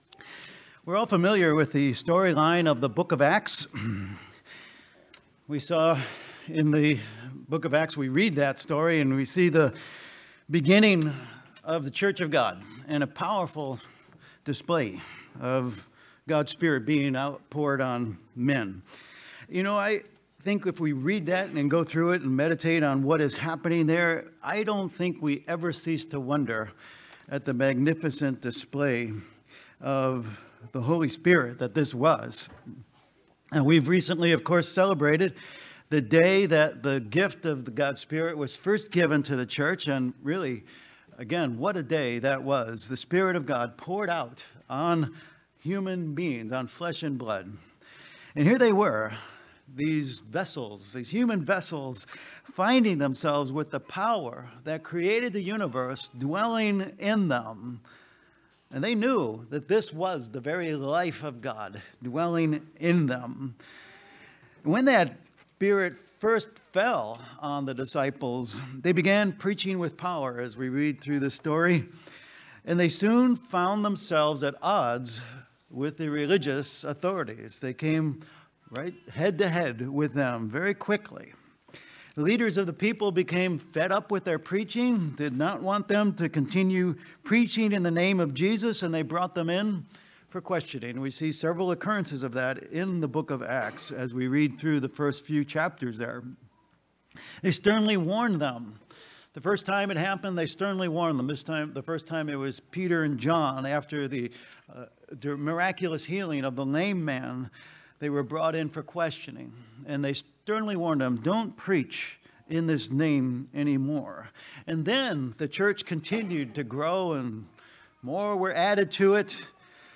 Sermons
Given in Worcester, MA